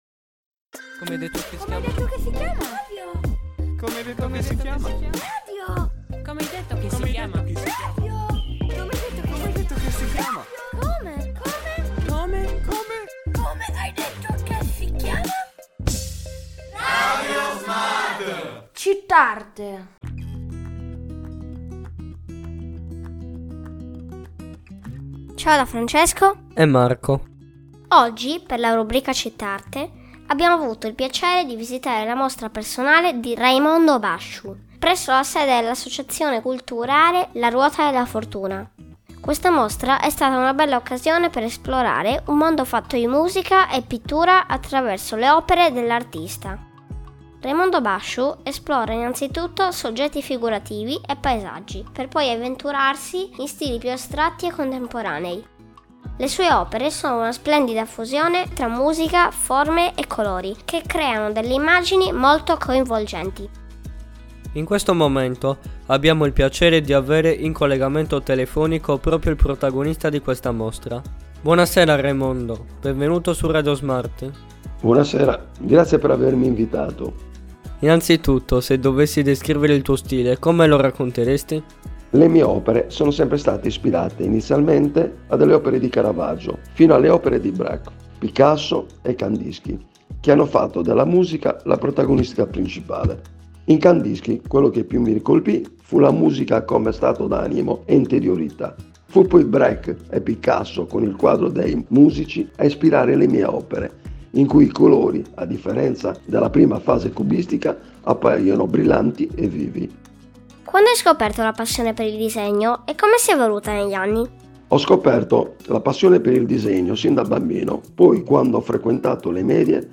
In questa intervista parleremo della nascita di questa grande passione e delle infulenze che hanno caratterizzato il suo stile.